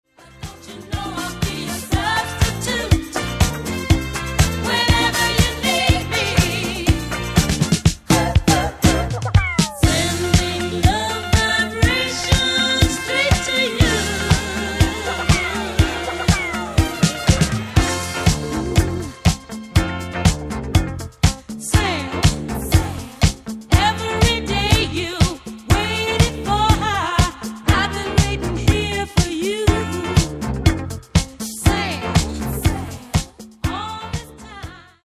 Genere:   Disco | Soul |